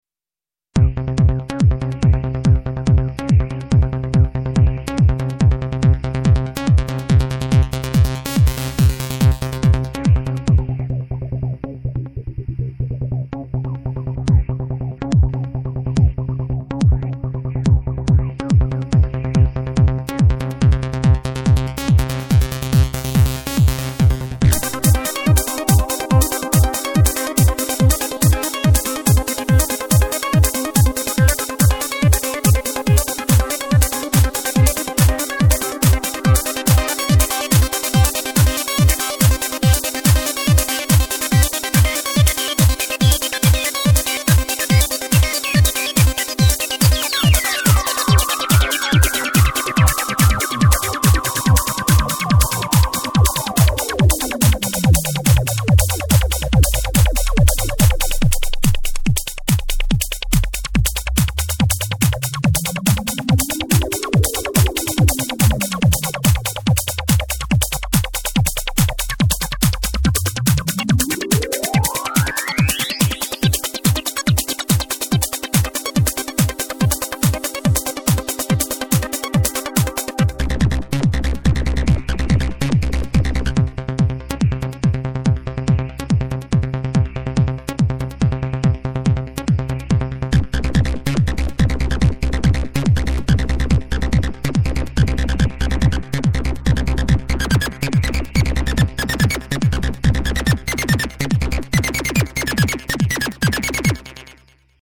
Demo-Combi-Velo-psychedelic.mp3